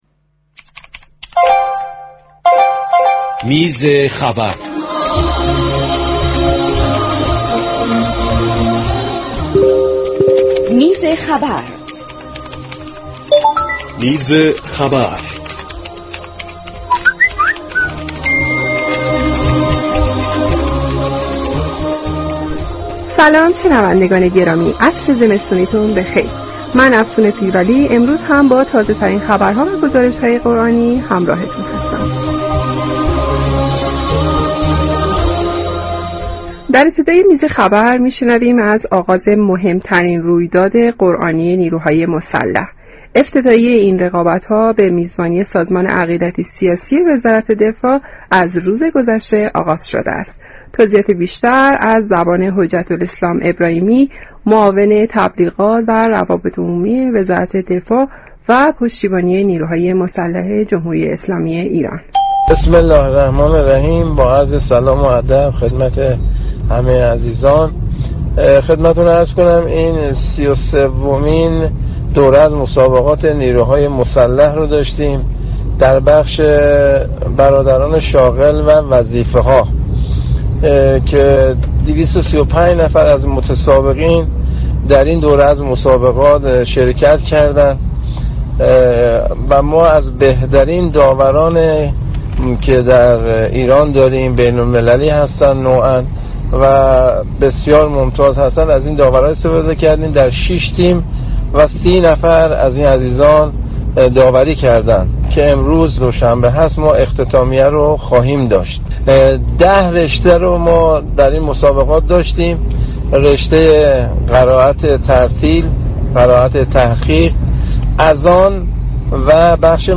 "میز خبر" عنوان آیتمی از برنامه والعصر رادیو قرآن است كه به تولید و انعكاس اخبار و رویداد های قرآنی می پردازد.